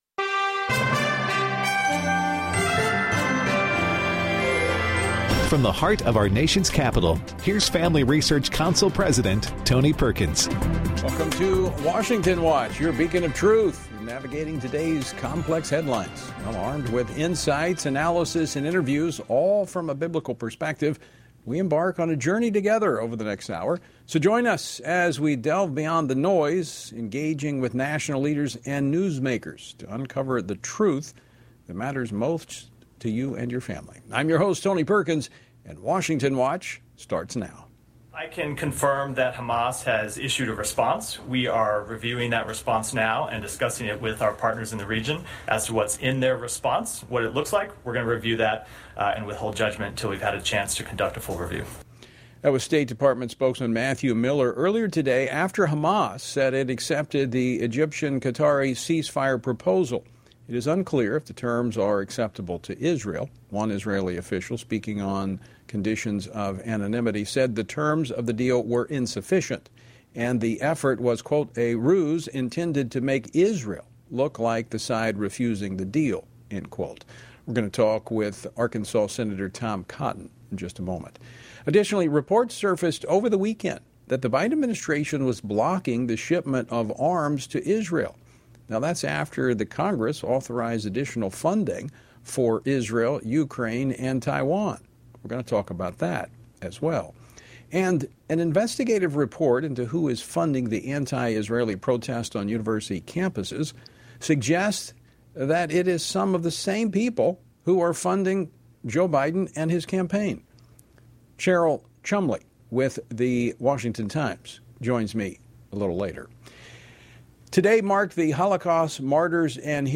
On today’s program: Tom Cotton, U.S. Senator from Arkansas, discusses the latest developments from Israel’s war against Hamas and reacts to the Biden administration putting a hold on a munition shipment to Israel.